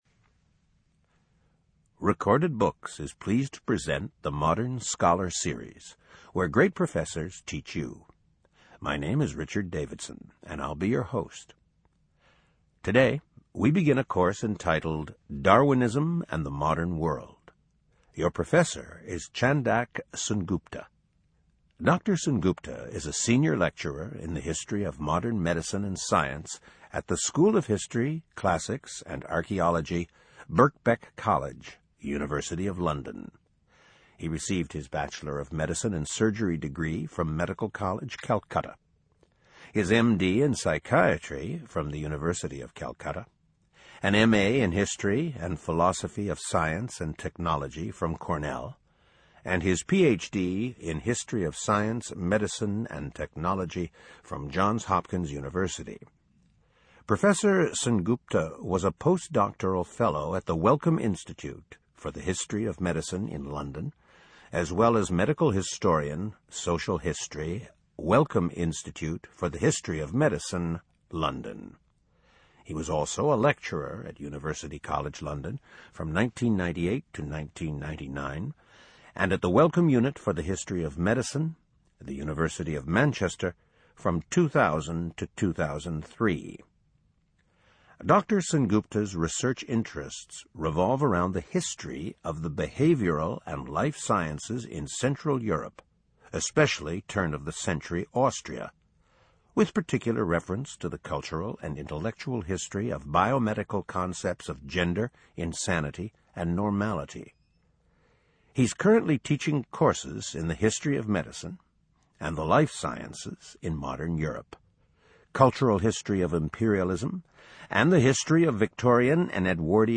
Audio Lecture Series · Darwin, Darwinism and the Modern World 01 · Demo Gallery